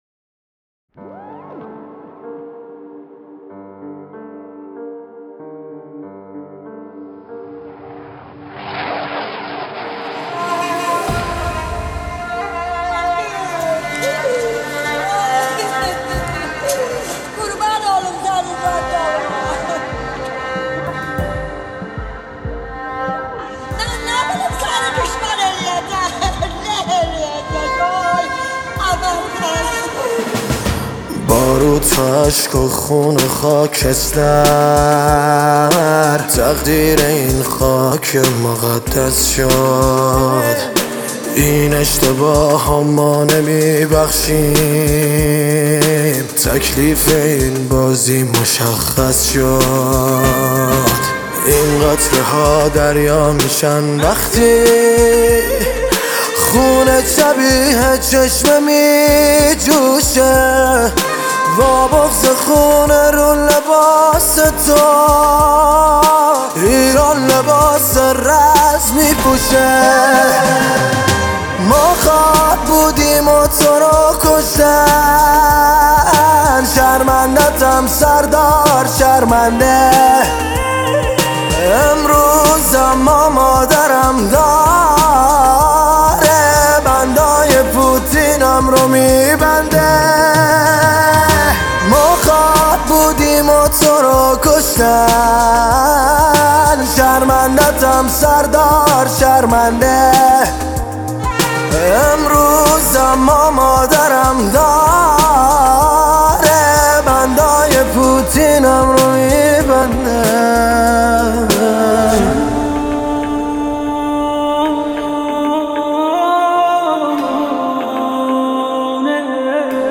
دانلود ریمیکس